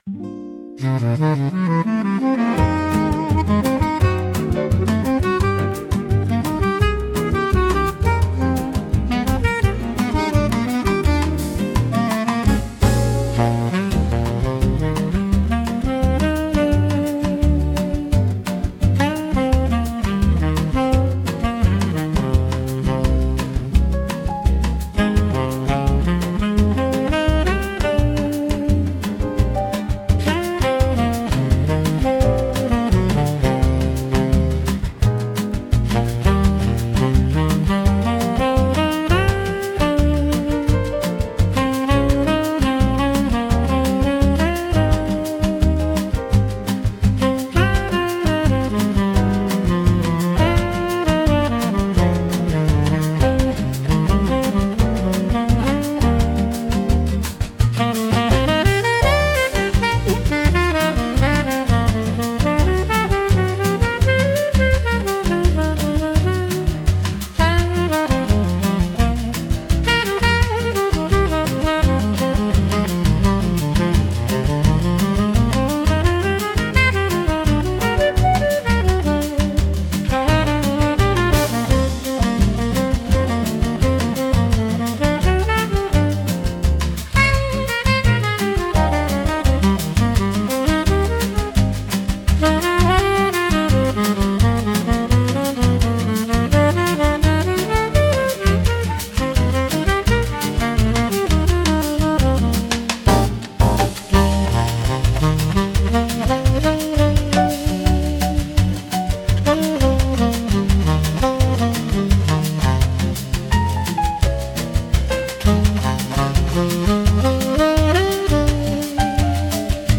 música, arranjo e voz: IA